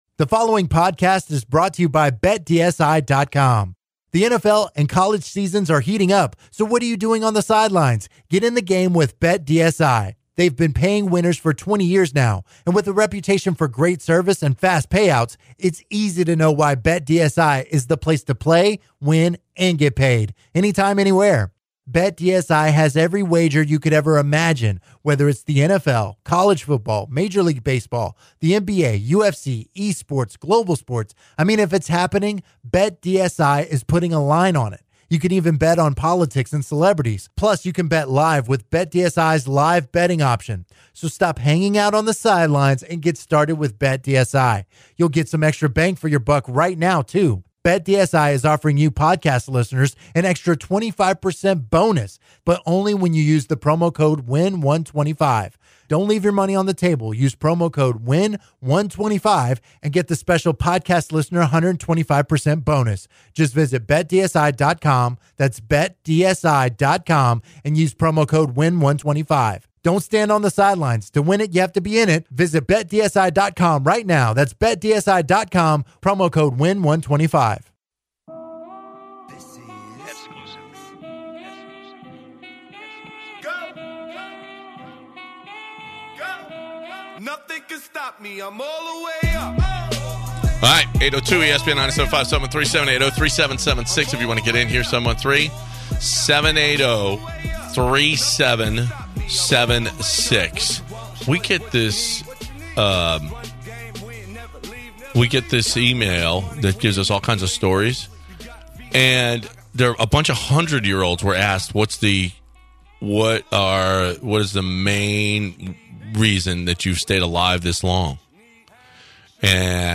To begin the second hour of the show, the guys share some stories about “old school” coaching. After the opening segment, the hilarity continues as they take a bunch of callers who have great coaching stories. In the second half of the hour, they discuss the OU-Texas game and the importance of the Texans game against the Cowboys for the city of Houston.